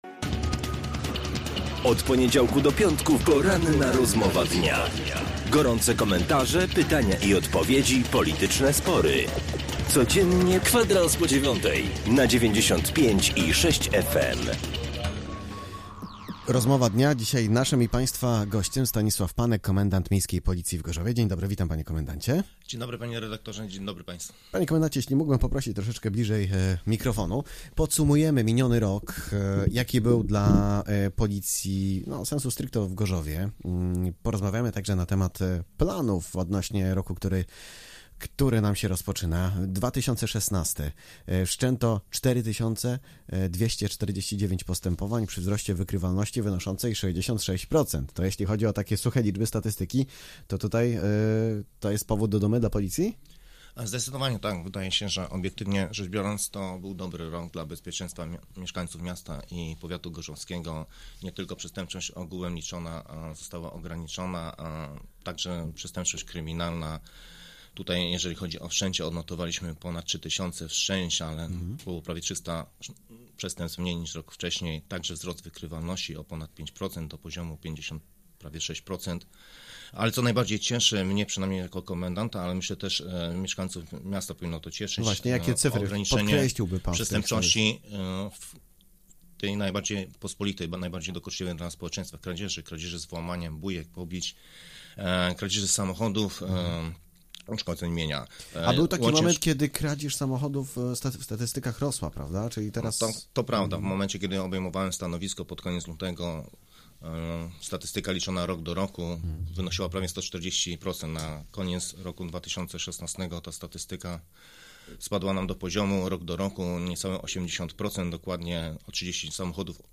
W czwartek w studiu Radia Gorzów gościł insp. Stanisław Panek- Komendant Miejski Policji w Gorzowie Wlkp.